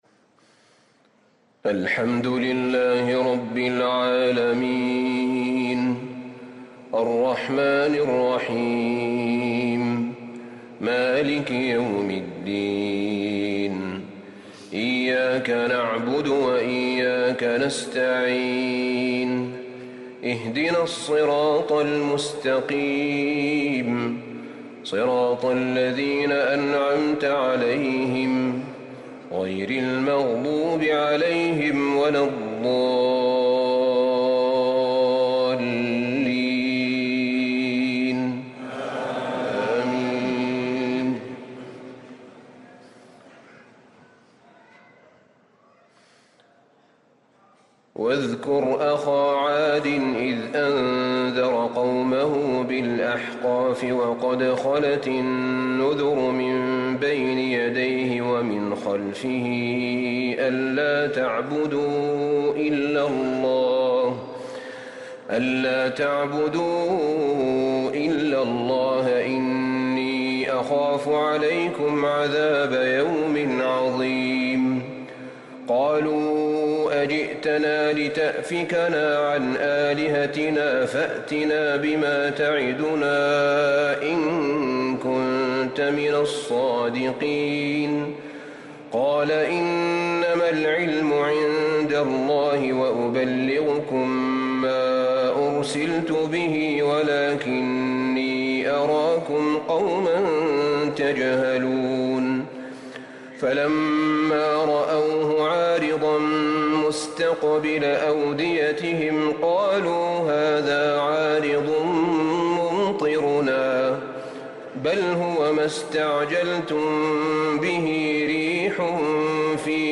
تراويح ليلة 27 رمضان 1444هـ من سور الأحقاف (21-35) و محمد و الفتح (1-17) | Taraweeh 27 st night Ramadan 1444H Surah Al-Ahqaf and Muhammad And Al-fath > تراويح الحرم النبوي عام 1444 🕌 > التراويح - تلاوات الحرمين